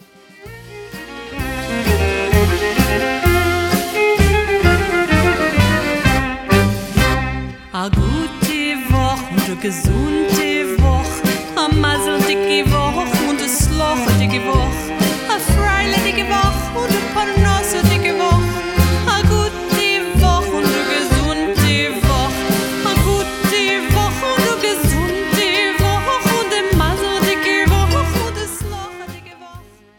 Recorded with top Israeli session players.